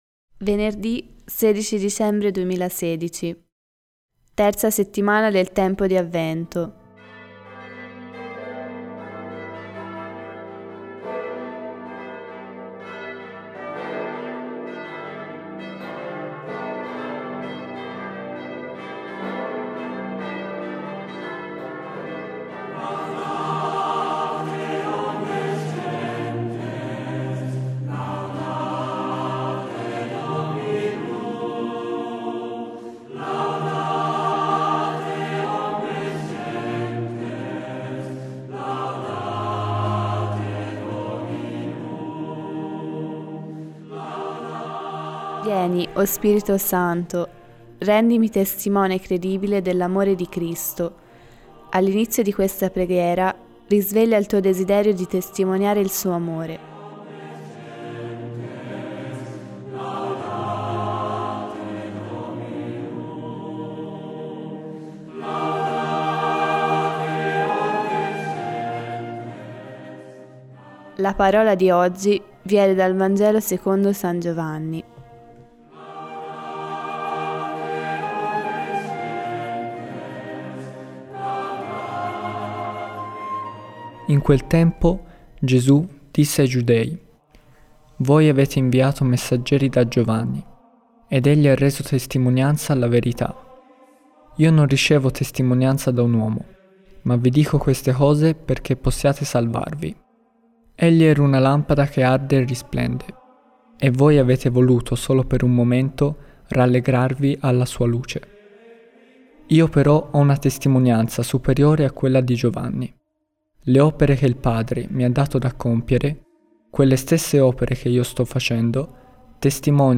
Musica: Laudate Omnes Gentes – Taize